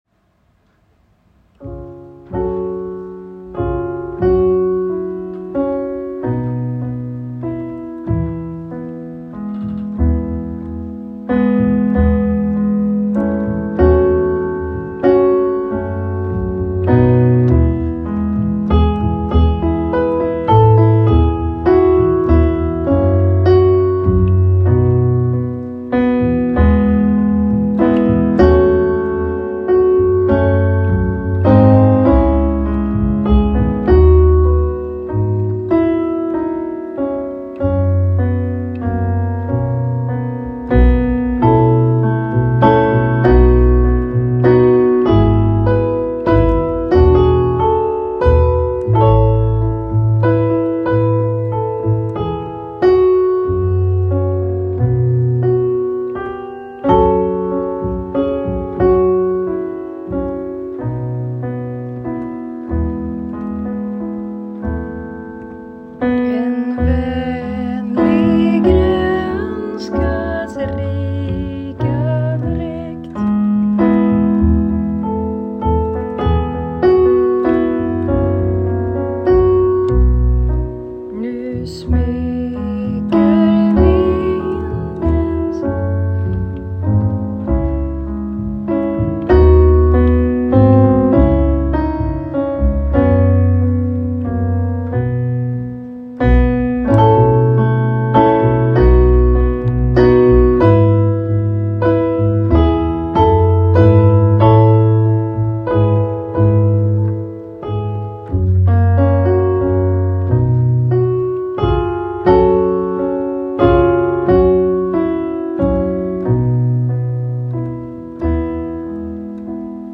Ljudfil pianokomp i E-dur, första versen är Ooo-vers.
En-vanlig-gronskas-rika_pianokomp.m4a